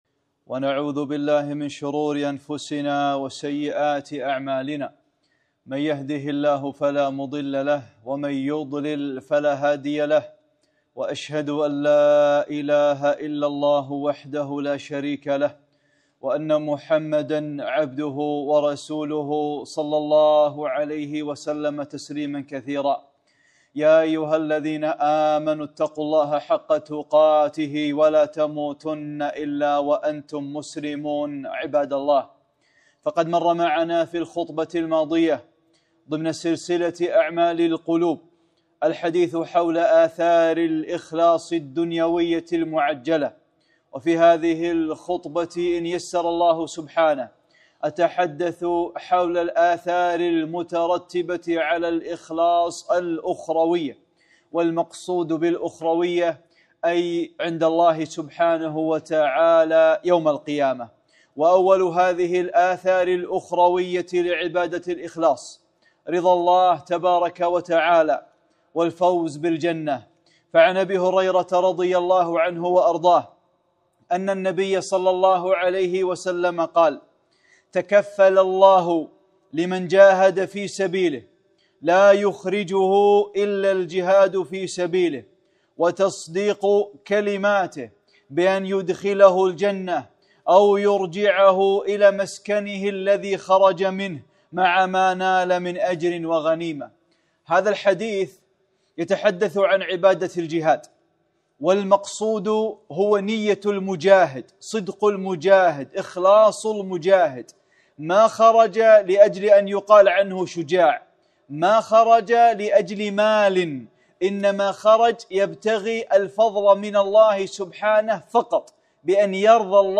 (14) خطبة - الآثار الآخروية للإخلاص